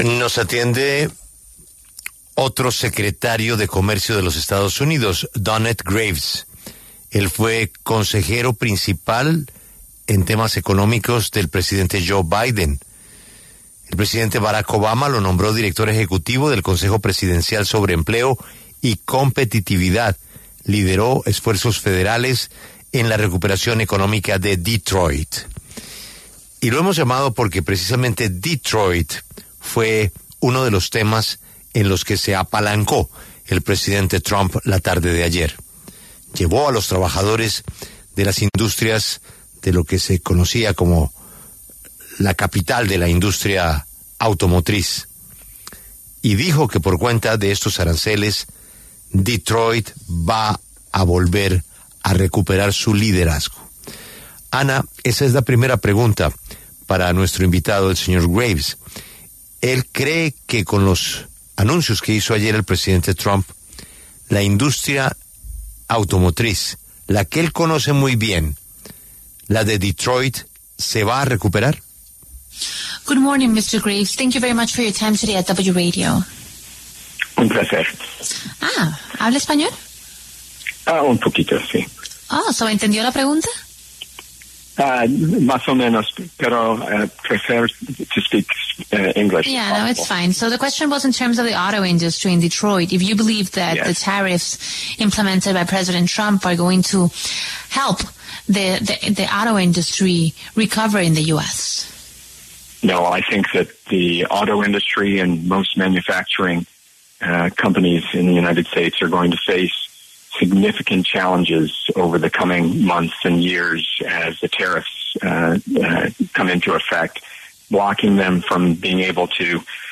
Este jueves, 3 de abril, Donet Dominic Graves, ex subsecretario de Comercio de los Estados Unidos, habló en La W, con Julio Sánchez Cristo, y se refirió al tema que hoy por hoy marca la pauta en la agenda internacional: los aranceles que Donald Trump impuso a varios países.